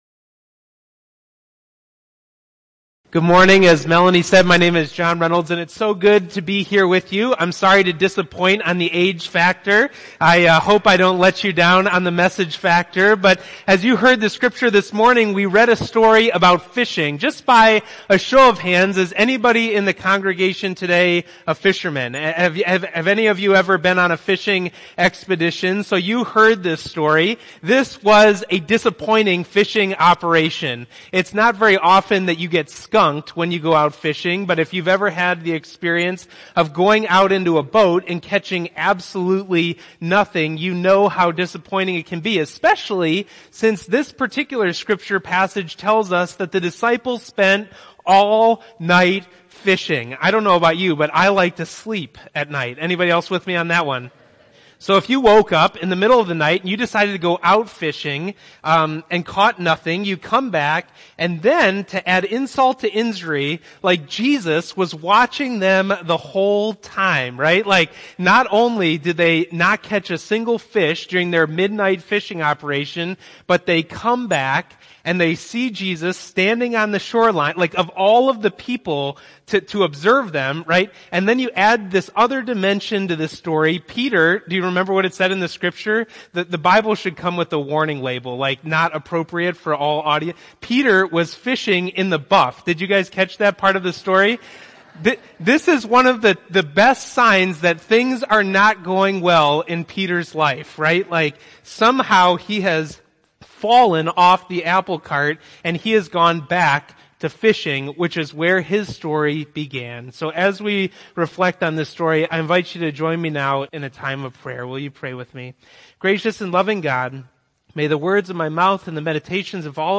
Oct1418-Sermon.mp3